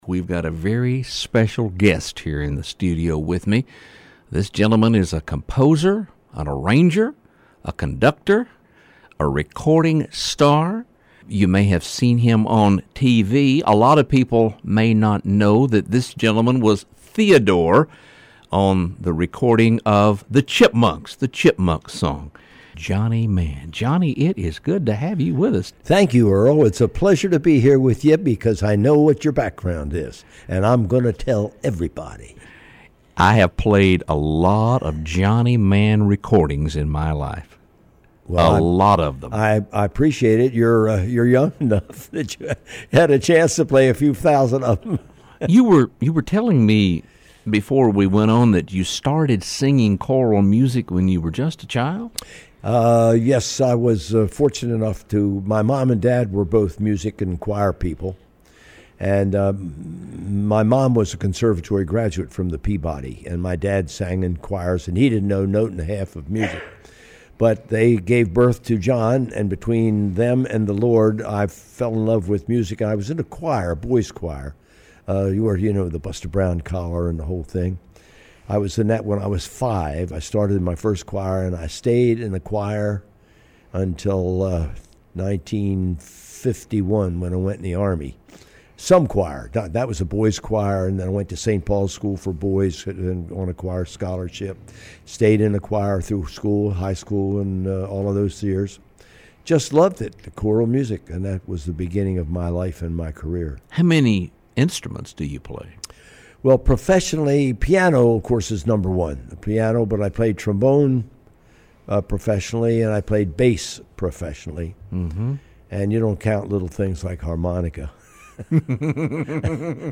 In a radio interview with this writer, Mr. Mann said he was most proud of his Christian recording of “Quiet Time,” a collection of his favorite hymns.